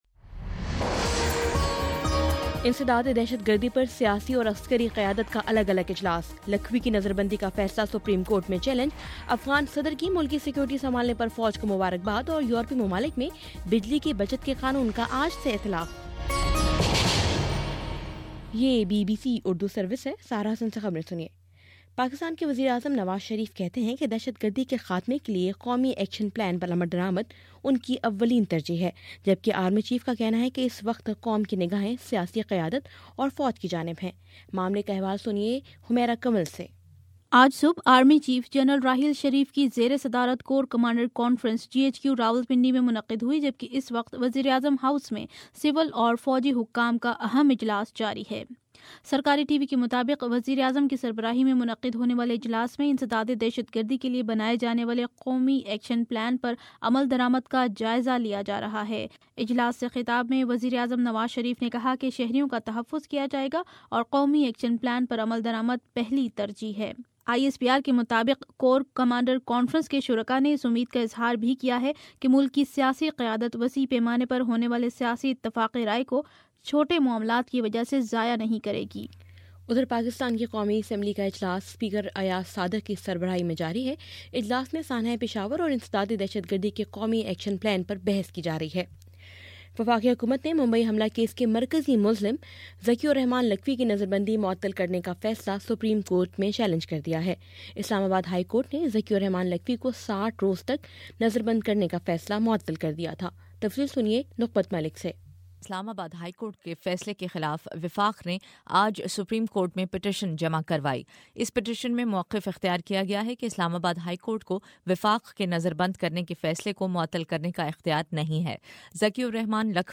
جنوری 01: شام چھ بجے کا نیوز بُلیٹن